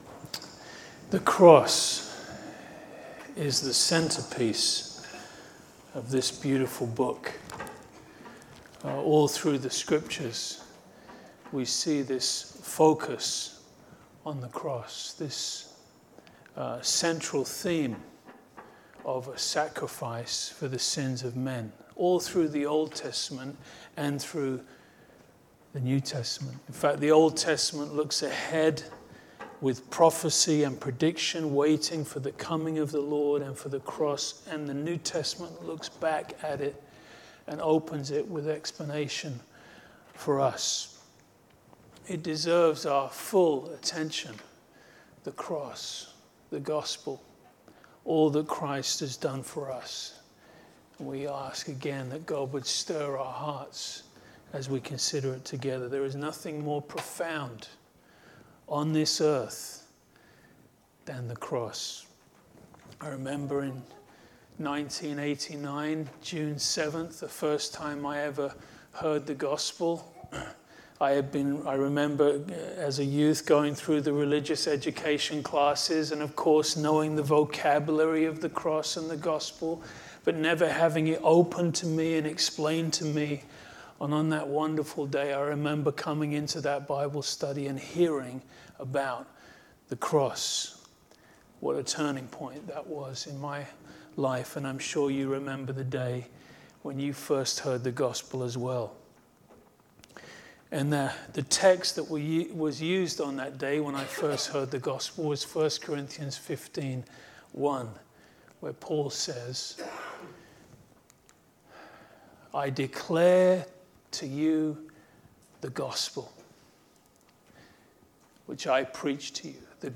Shadows of The Cross – A Good Friday Sermon